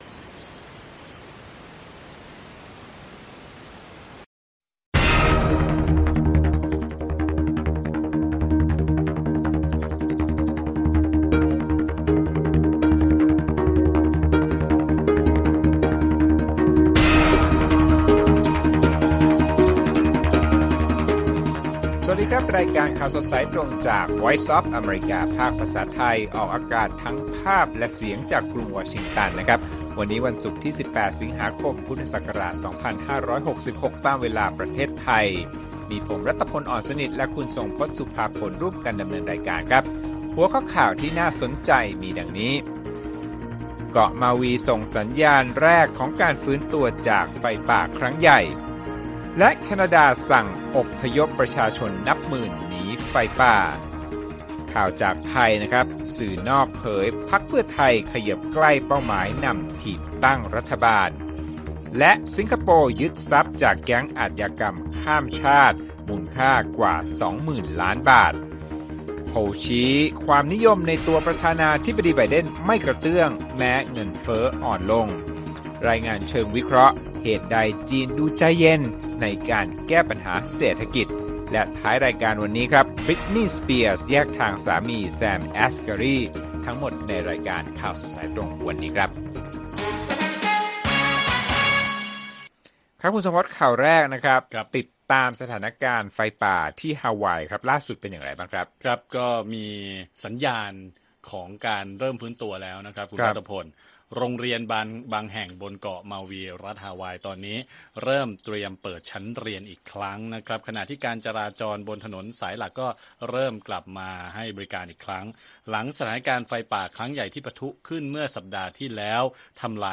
ข่าวสดสายตรงจากวีโอเอไทย 6:30 – 7:00 น. วันที่ 18 ส.ค. 2566